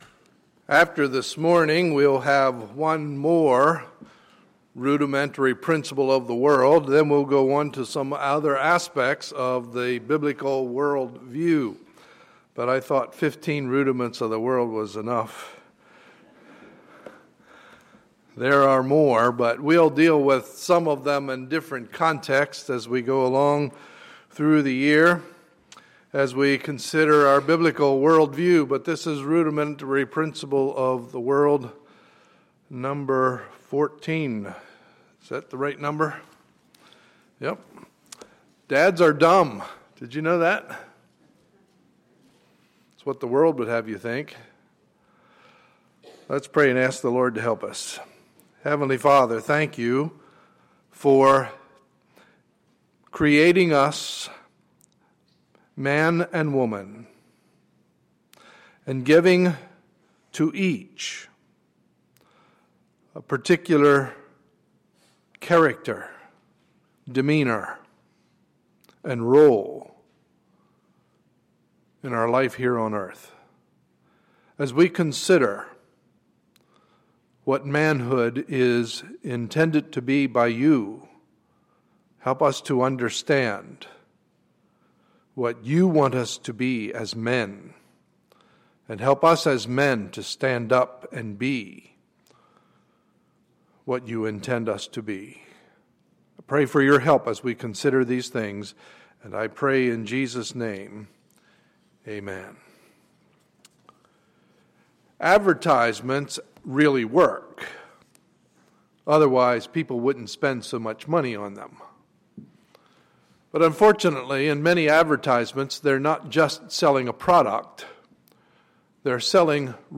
Sunday, June 15, 2014 – Morning Service